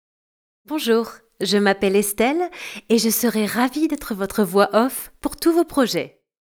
Une voix, plusieurs styles
Une voix douce, posée, rassurante, souriante, dynamique, punchy
Je dispose donc d’un studio de post-production à l’acoustique étudiée et parfaitement insonorisé.